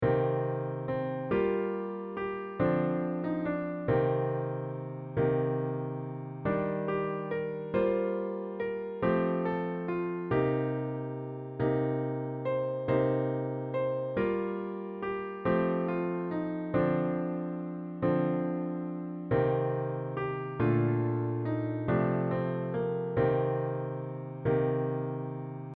Scaborough-Fair-Dor.mp3